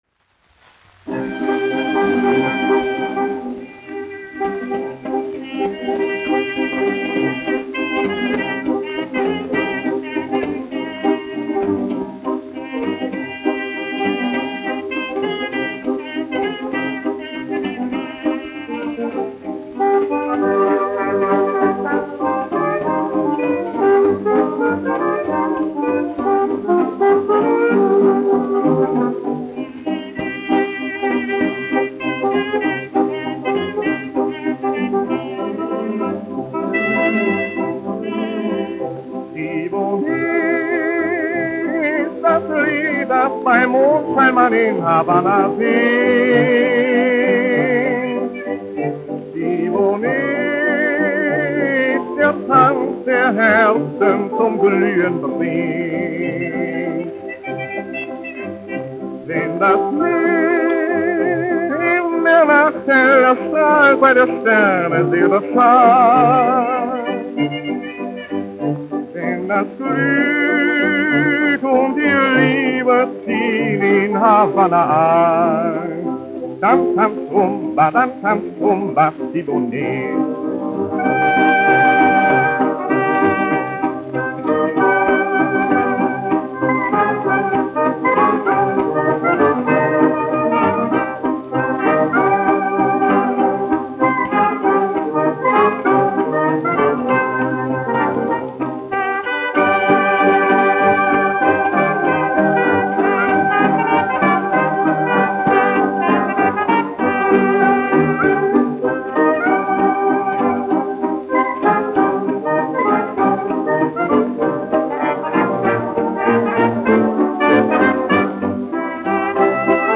1 skpl. : analogs, 78 apgr/min, mono ; 25 cm
Populārā mūzika
Rumbas (mūzika)
Latvijas vēsturiskie šellaka skaņuplašu ieraksti (Kolekcija)